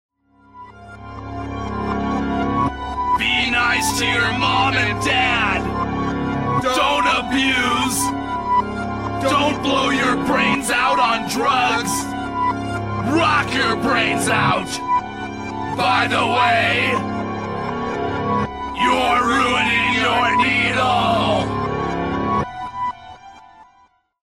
heavy metal
Tipo di backmasking Rovesciato